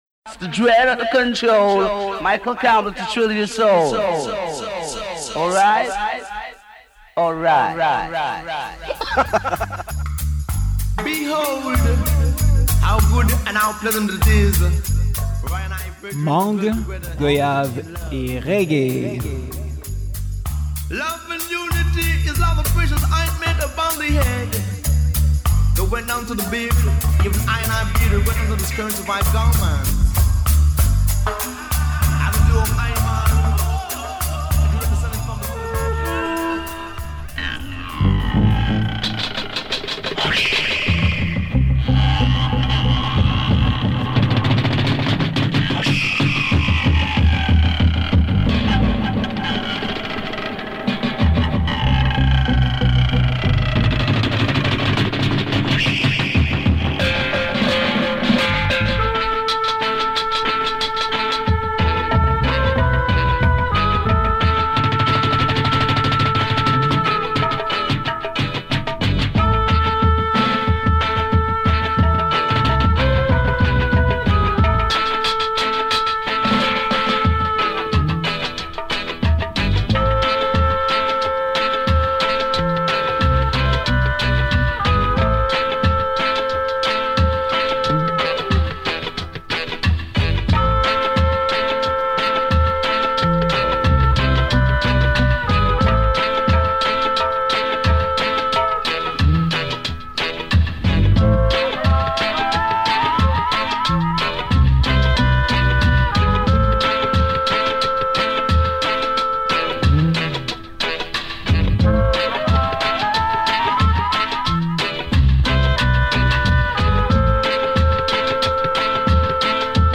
CETTE SEMAINE: Roots reggae (70s style)